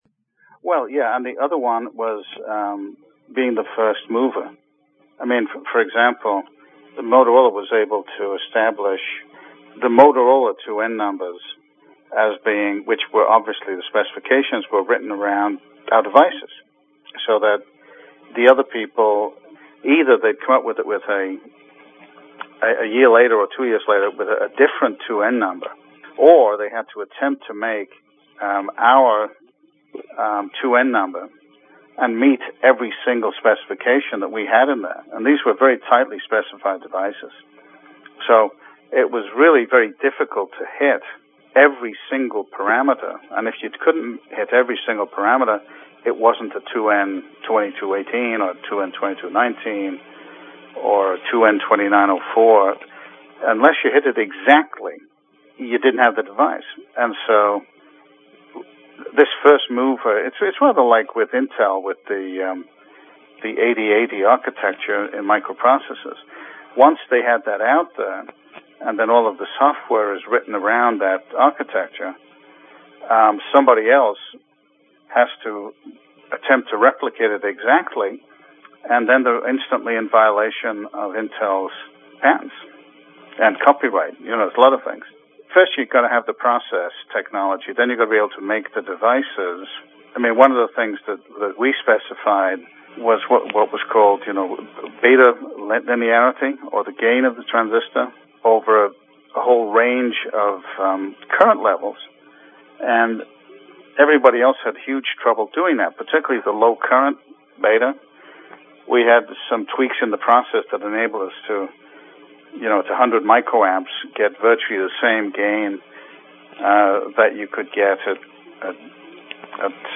A Transistor Museum Interview